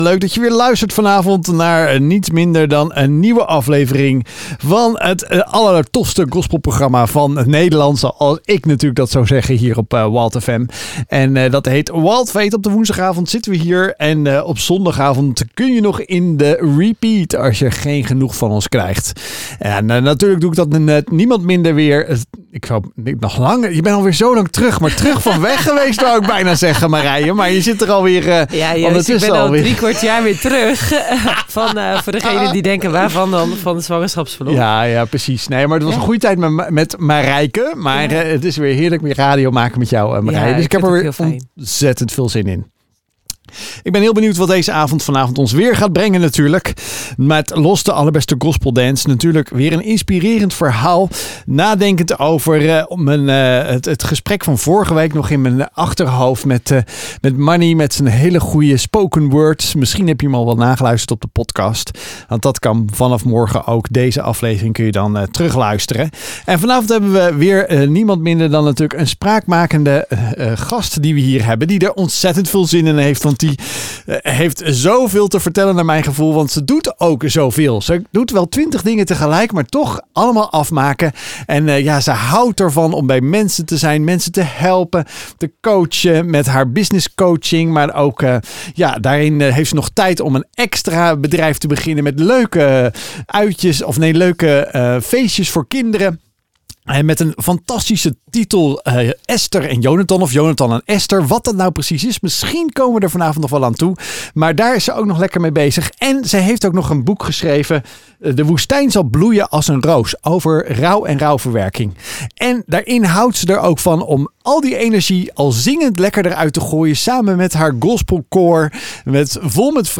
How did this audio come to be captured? Luister hier naar de uitzending op Wild FM over mijn leven als ondernemer en over mijn boek